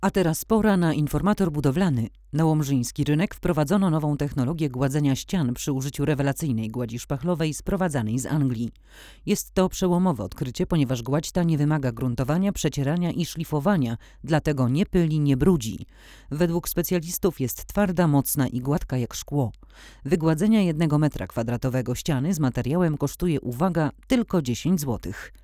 Jest czysto, gładko i wyraźnie, a jednocześnie naturalnie.